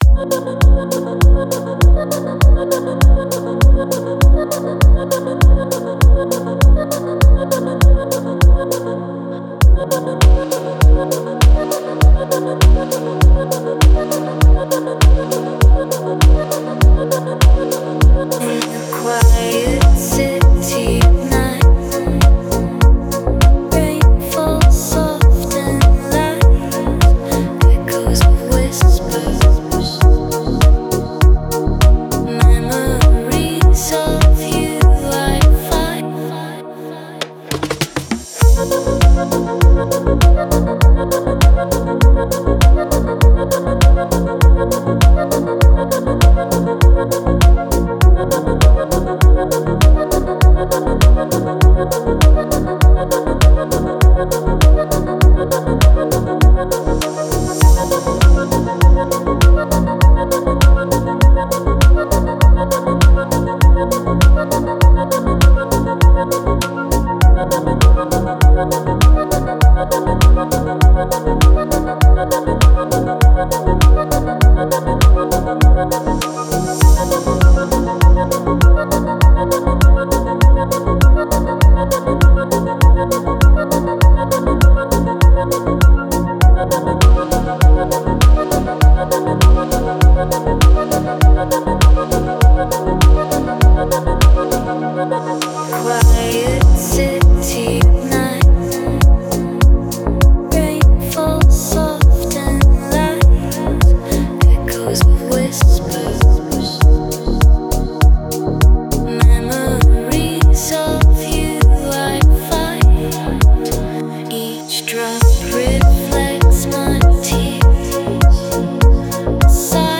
Deep House
Дип Хаус музыка